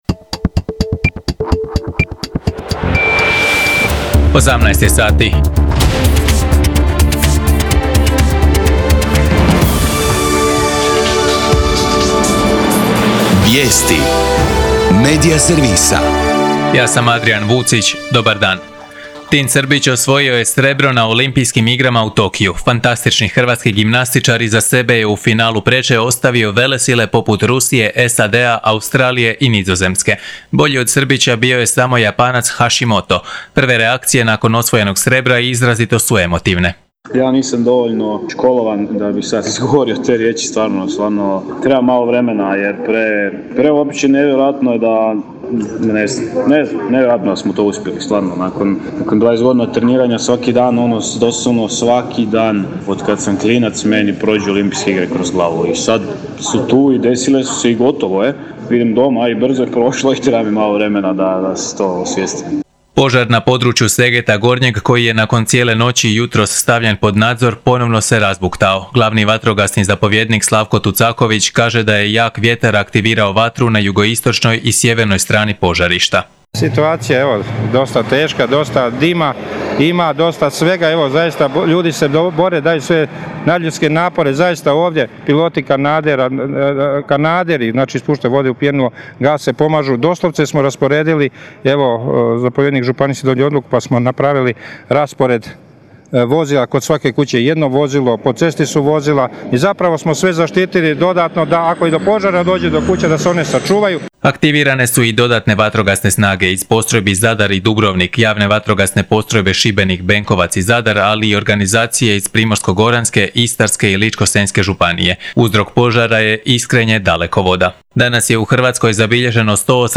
VIJESTI U 18